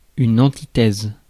Ääntäminen
Ääntäminen France: IPA: [ɑ̃.ti.tɛz] Haettu sana löytyi näillä lähdekielillä: ranska Käännös Substantiivit 1. antítesis {f} Suku: f .